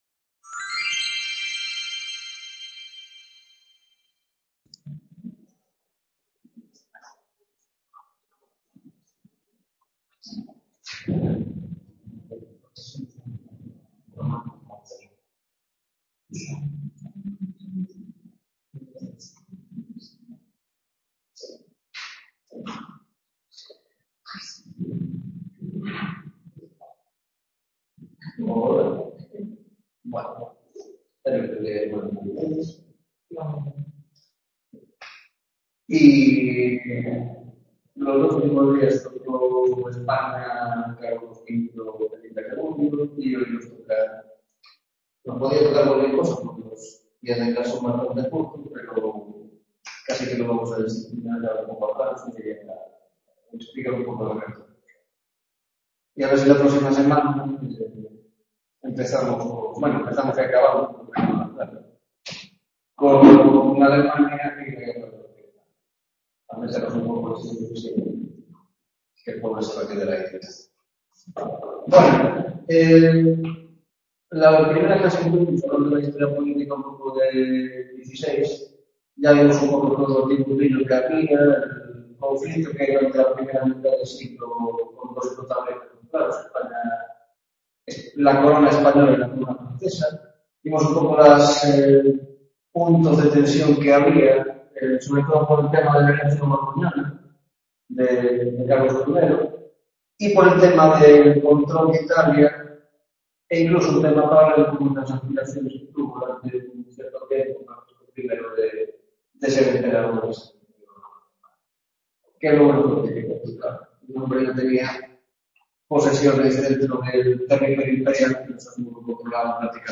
9ª Tutoría Historia de la Alta Edad Moderna - Guerras de Religión